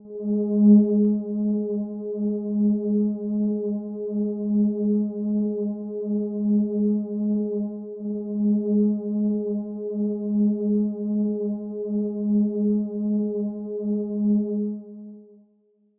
描述：合成器/铃垫型循环，A调
Tag: 120 bpm RnB Loops Pad Loops 2.69 MB wav Key : Unknown